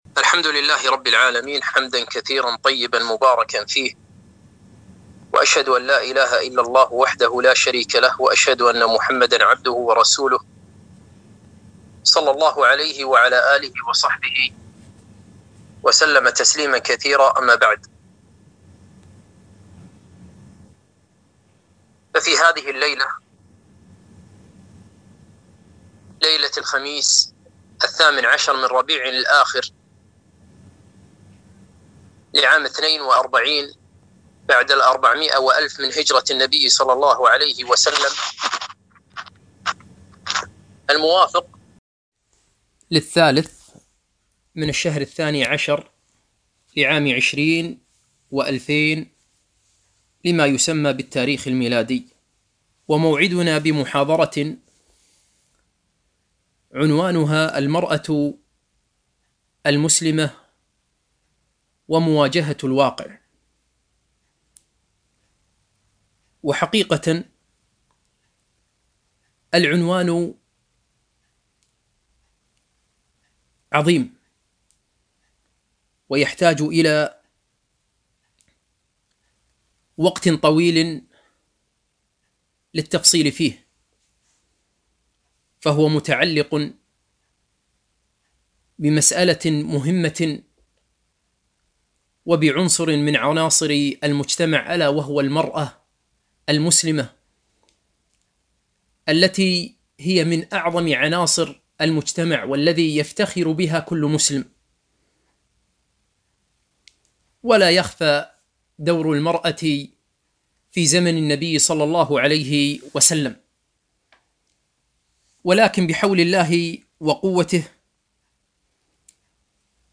محاضرة - المرأة المسلمة ومواجهة الواقع